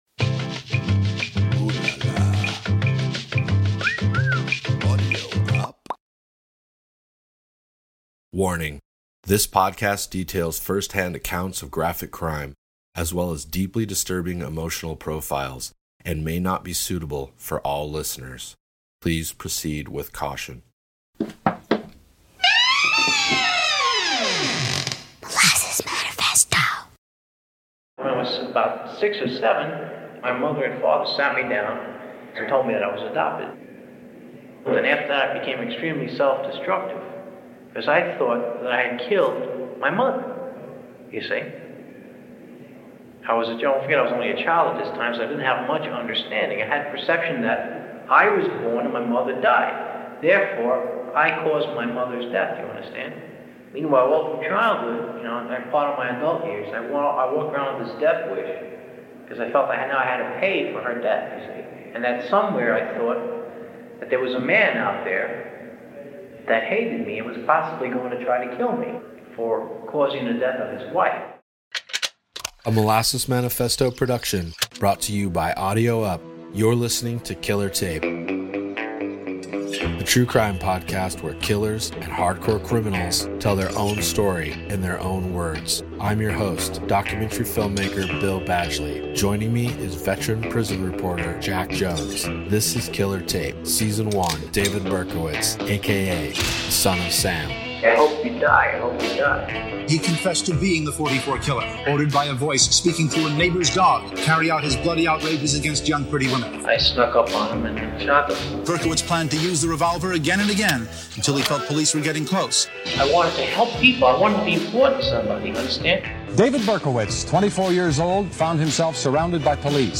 As the interview begins, David divulges the interworking of his nuclear family, as well as the news of the adoption that will serve to derail his fragile psyche.